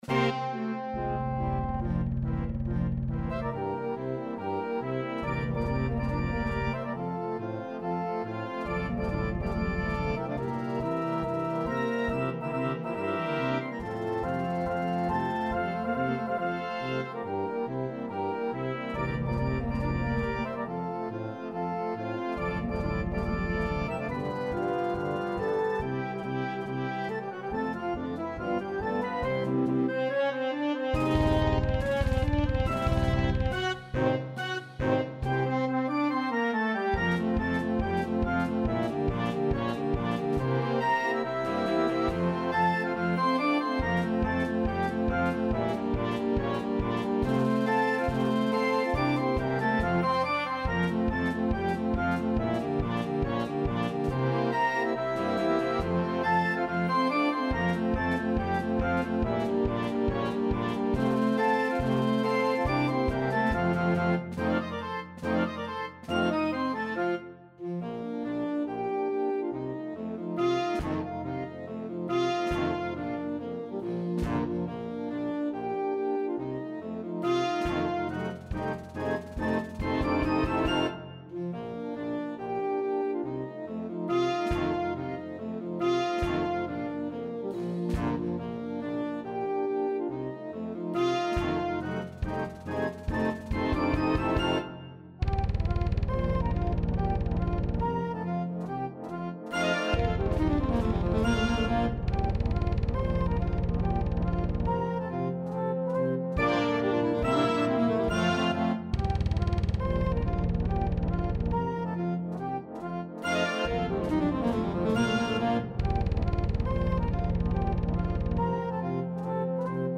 Schnellpolka